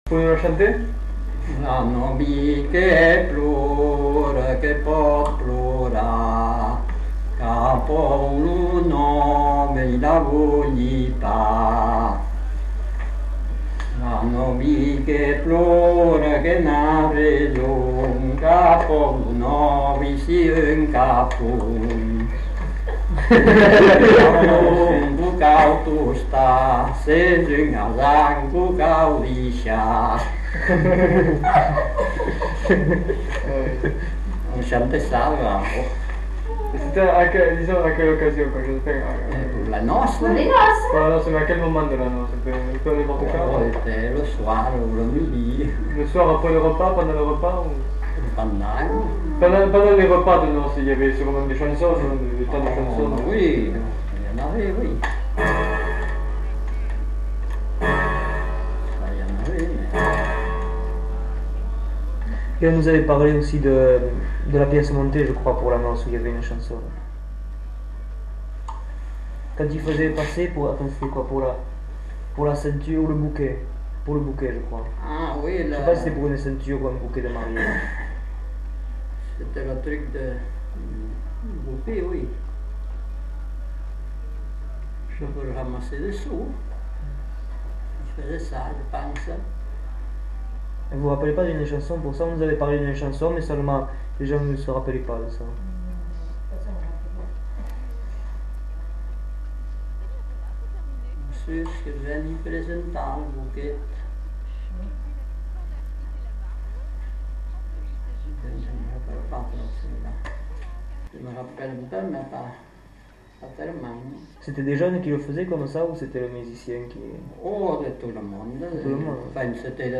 Aire culturelle : Petites-Landes
Lieu : Lencouacq
Genre : chant
Effectif : 1
Type de voix : voix d'homme
Production du son : chanté
Notes consultables : En fin de séquence, discussion sur la coutume du bouquet.